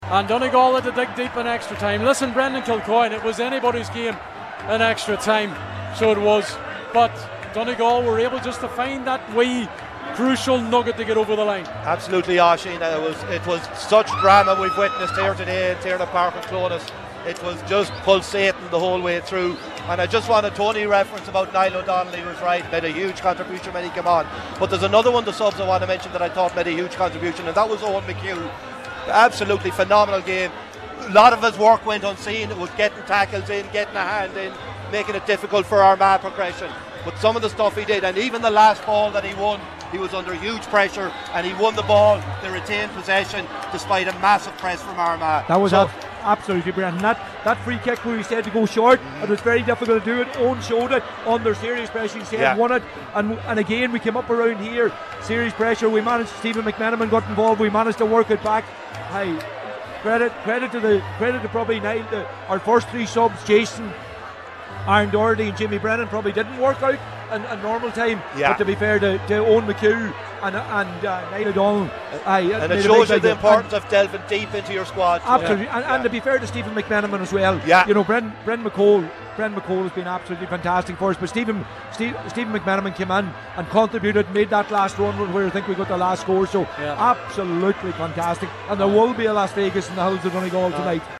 immediate post-match reaction